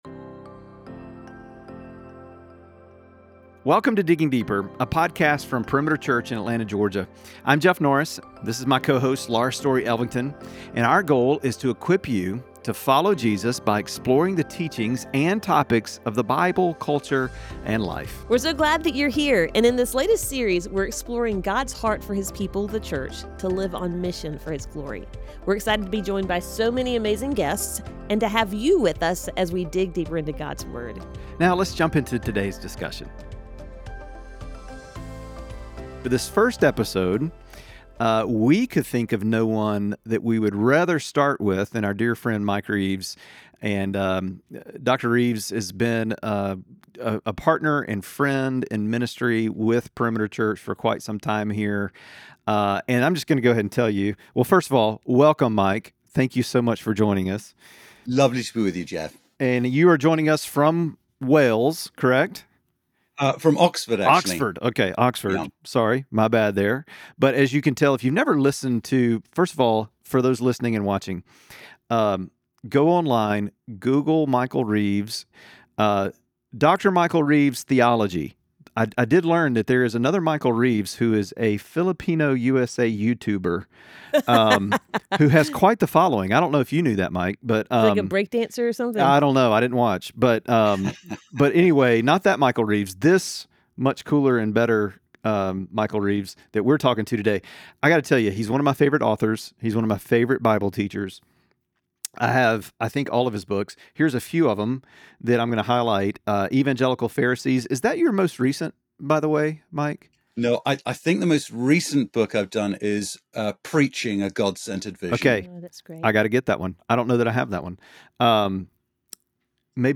They discuss how delighting in God naturally fuels a life on mission, making gospel-sharing a joyful overflow rather than a duty. Tune in for a rich conversation on how true mission stems from a heart that enjoys God.